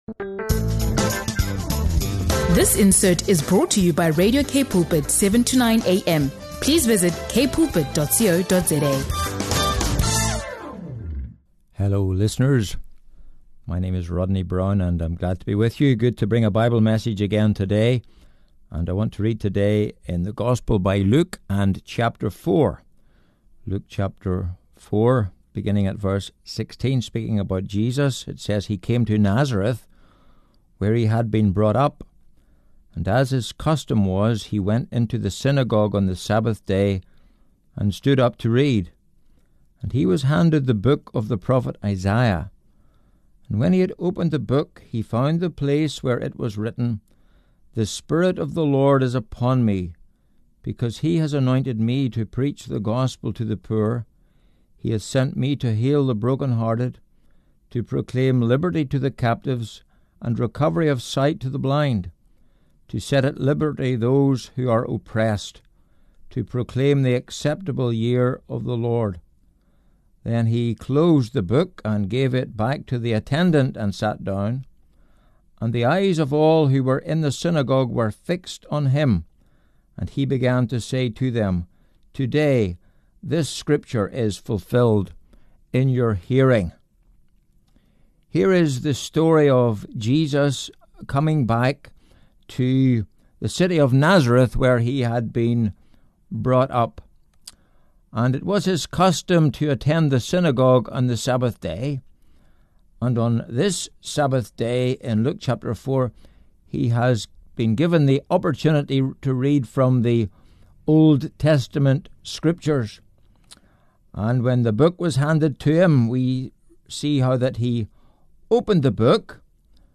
Bible message